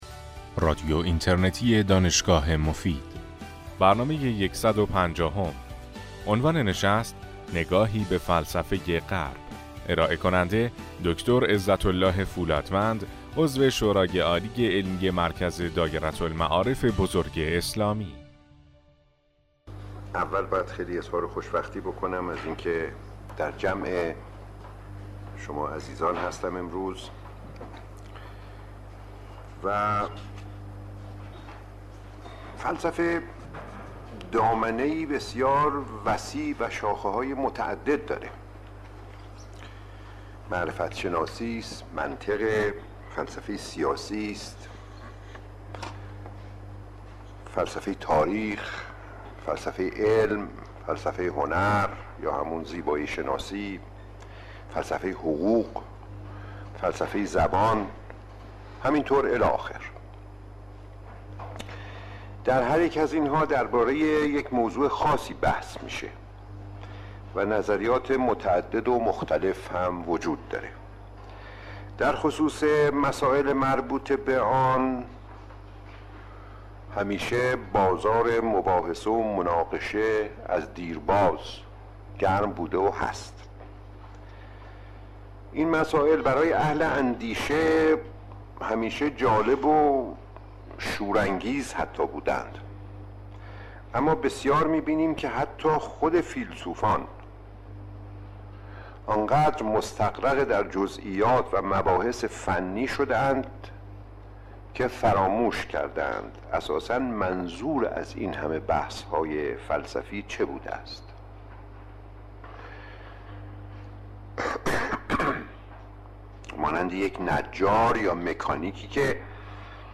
دکتر فولادوند در این سخنرانی، مشغول شدن فیلسوفان به جزییات فلسفی و دور شدن آنان ار توجه به غایت فلسفه را از آفات این حوزه می داند و مفهوم غایت فلسفه را تبیین می نمایند. ایشان در ادامه بحث خود با اشاره به آرای فیلسوفان تمدن یونان بحثی را پیرامون رابطه خاص فلسفه و علم مطرح می نمایند.